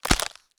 BREAK_Fast_stereo.wav